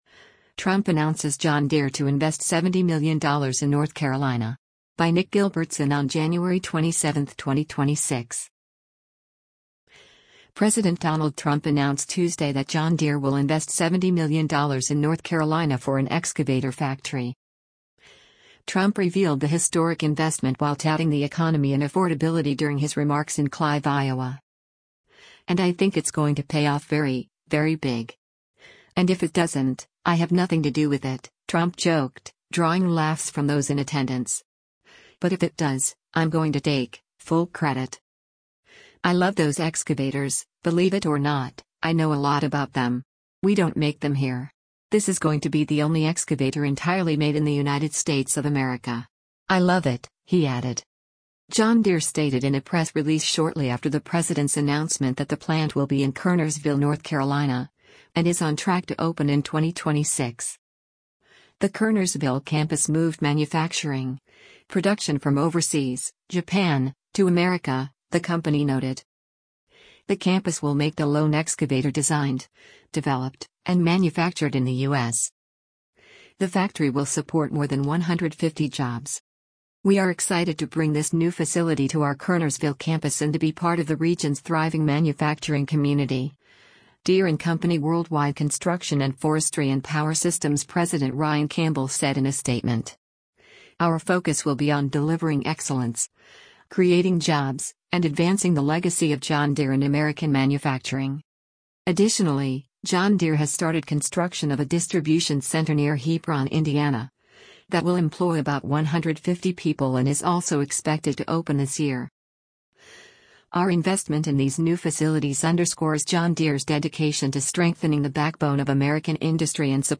Trump revealed the “historic investment” while touting the economy and affordability during his remarks in Clive, Iowa.
“And I think it’s going to pay off very, very big. And if it doesn’t, I have nothing to do with it,” Trump joked, drawing laughs from those in attendance. “But if it does, I’m going to take…full credit.”